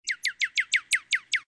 SZ_TC_bird1.ogg